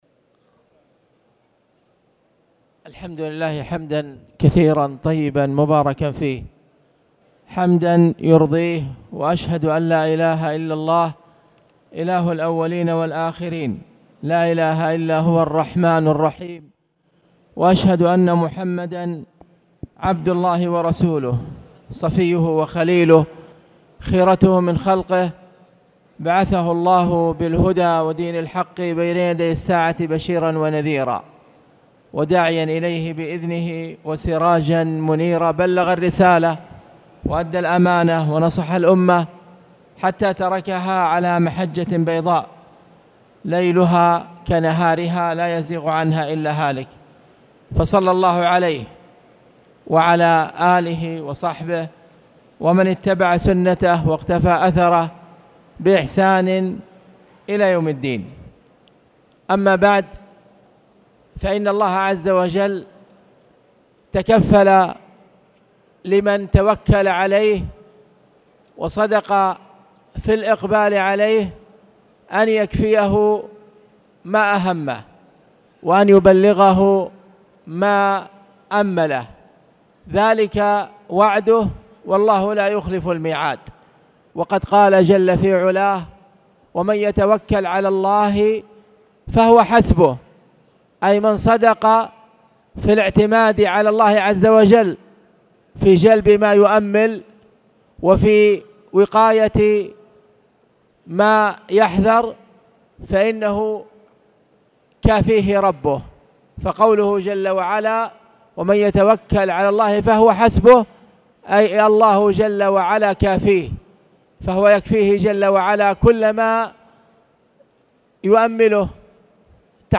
تاريخ النشر ٣ ربيع الأول ١٤٣٨ هـ المكان: المسجد الحرام الشيخ